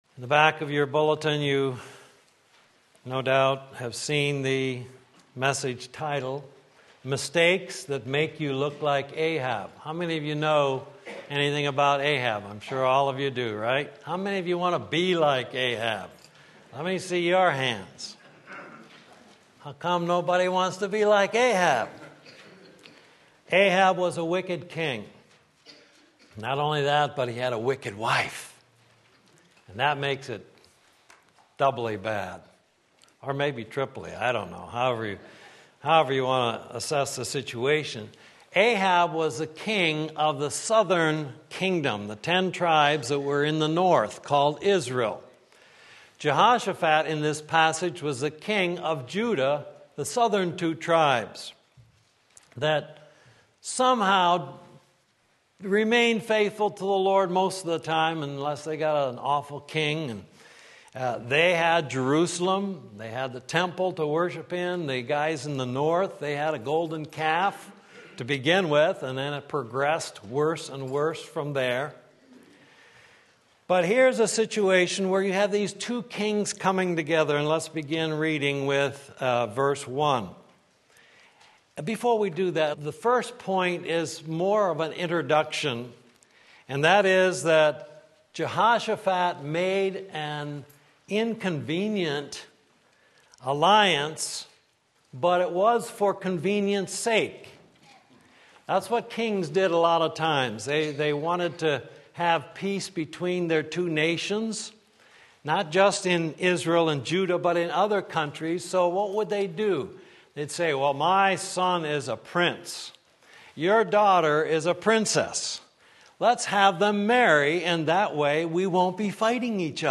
Sermon Link
2 Chronicles 19:1-2 Sunday Morning Service